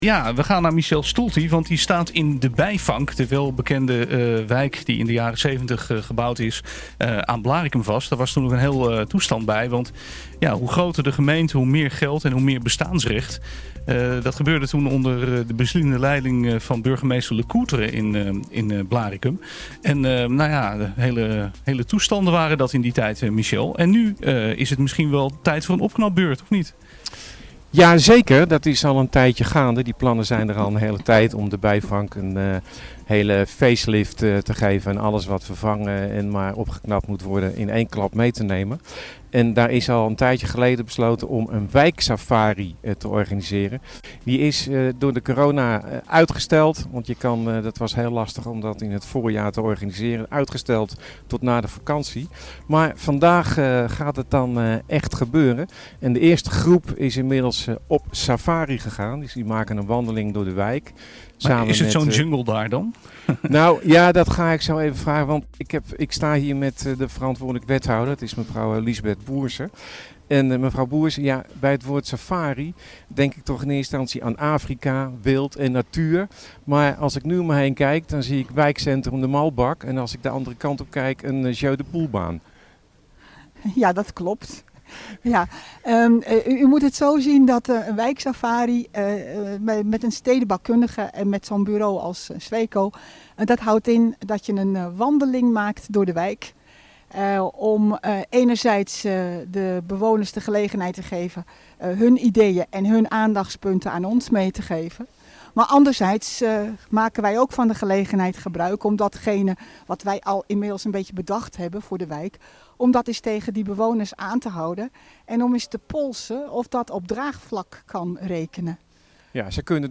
De resultaten van deze enquête zijn inmiddels bekend en vandaag vindt er een zogenaamde wijksafari (rondwandeling) plaats met vertegenwoordigers van de gemeente, van het stedebouw..